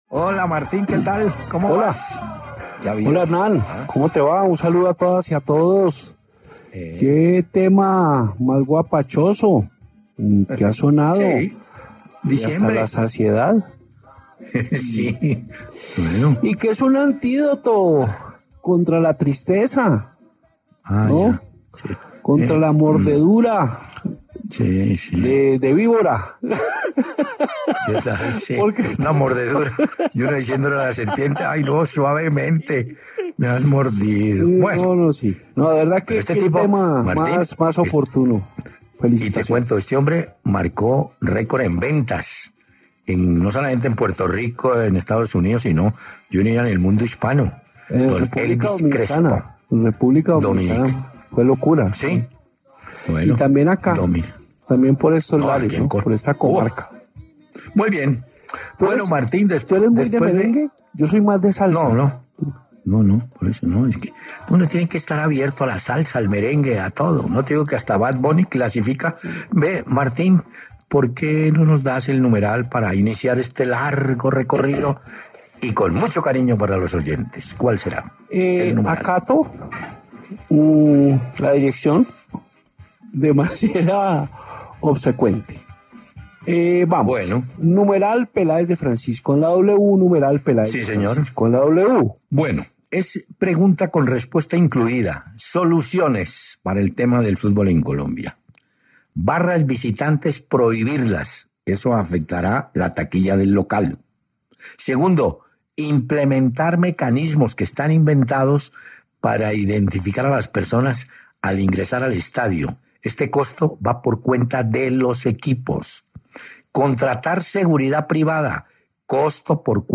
Hernán Peláez y Martín de Francisco hablan sobre las sanciones impuestas al América de Cali y su hinchada por los disturbios en la final de la Copa Colombia.